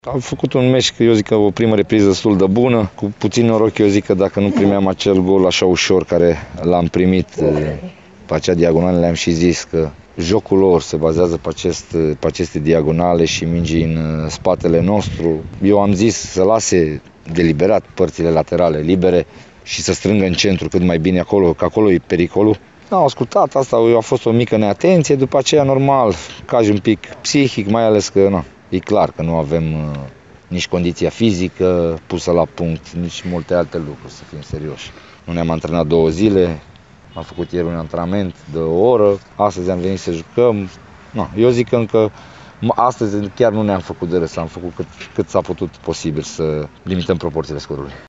Declaraţiile antrenorilor, după meci, le puteţi asculta alături